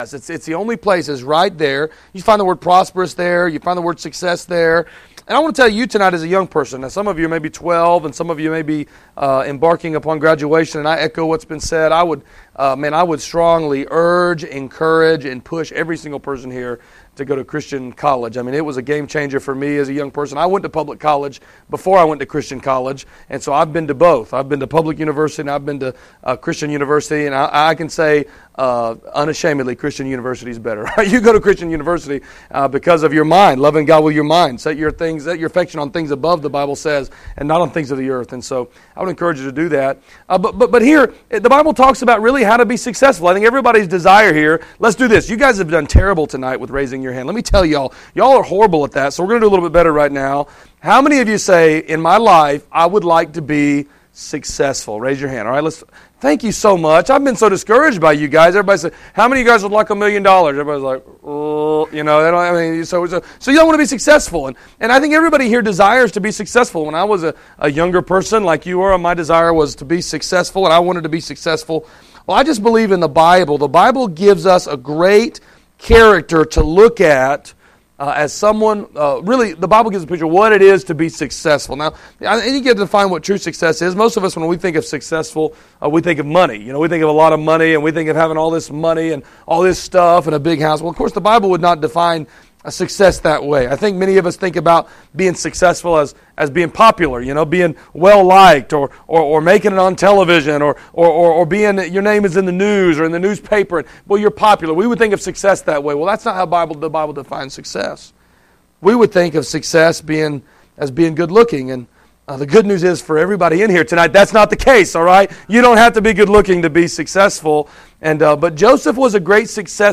Passage: Genesis 39 Service Type: Special Services Topics